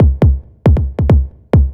Kick 137-BPM 2.wav